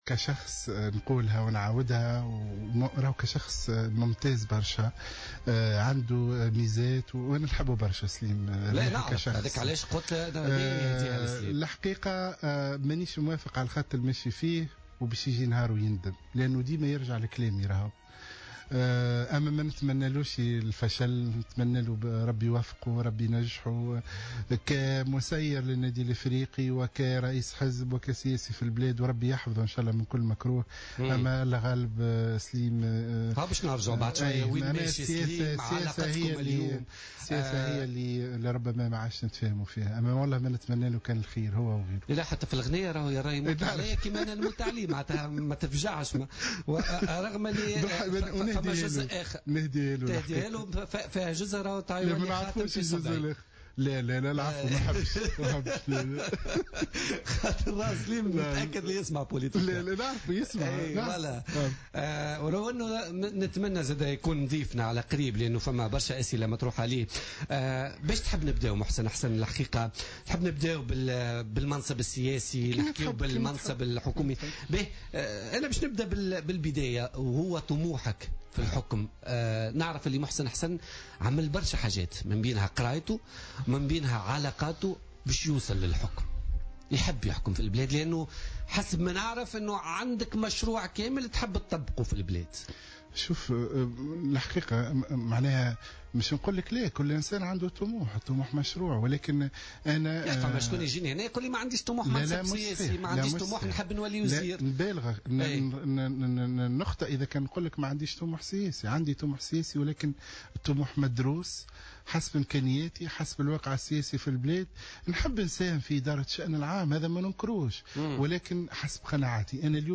وأضاف محسن حسن، ضيف برنامج "بوليتيكا" اليوم الأربعاء : " لا اتفق معه في بعض النقاط واعتقد أنه سيندم في يوم من الأيام، لكني لا أتمنى له الفشل كسياسي وكمسيّر لفريق رياضي".